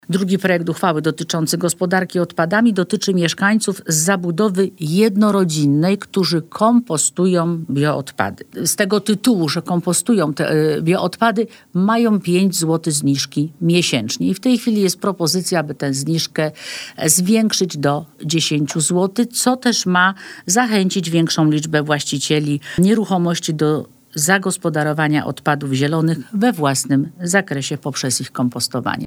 Wszystko było konsultowane z mieszkańcami – mówiła dziś na naszej antenie Dorota Piegzik-Izydorczyk, przewodnicząca Rady Miejskiej w Bielsku-Białej.